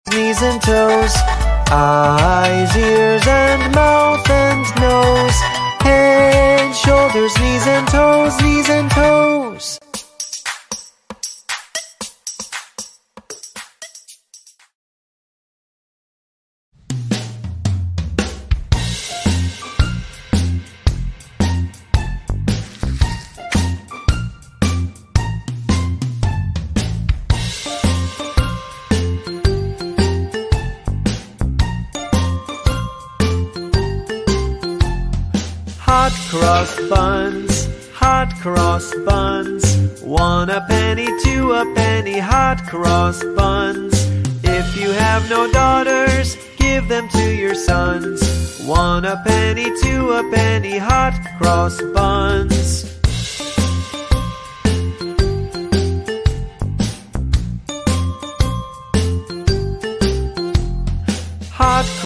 Kindergarten Nursery Rhymes for Kids